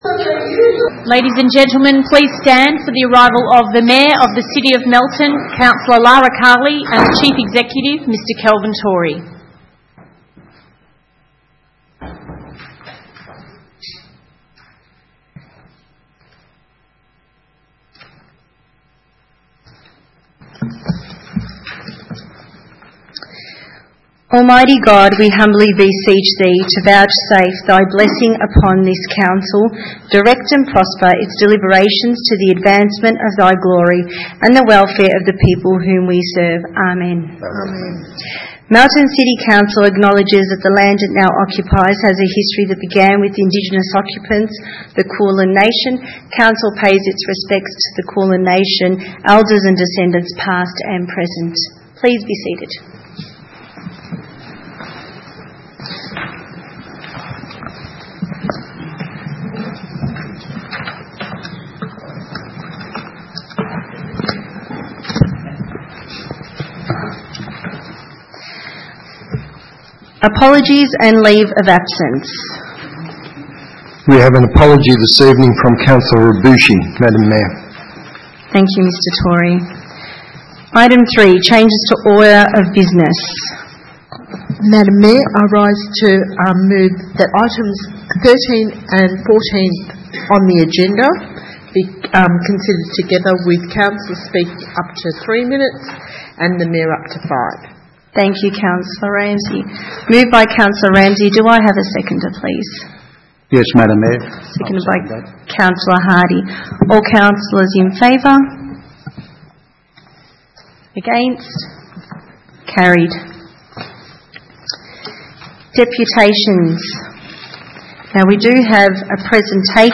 Ordinary Meeting 9 December 2019
Council Chambers, 232 HIgh Street, Melton, 3337 View Map
9-december-2019-council-meeting.mp3